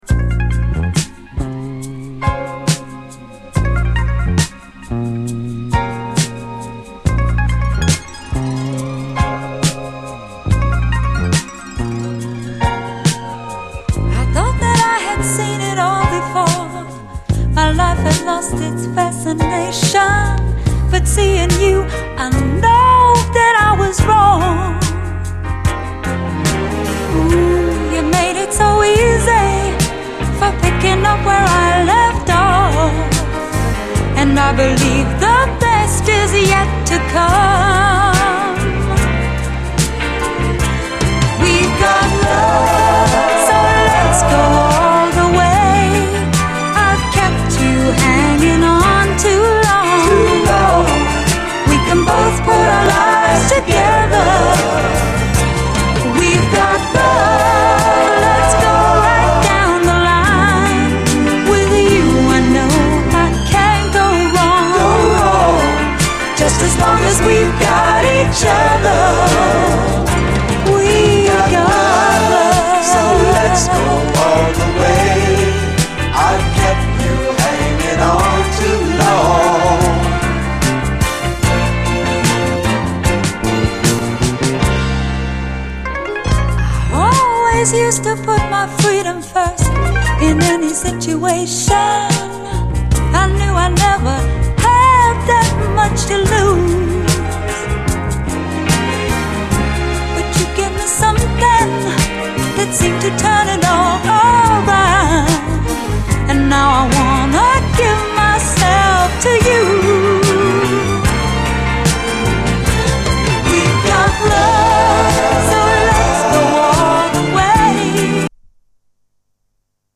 メロウ・レゲエ・カヴァー